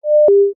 storageClose.ogg